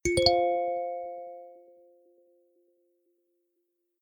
Chord.mp3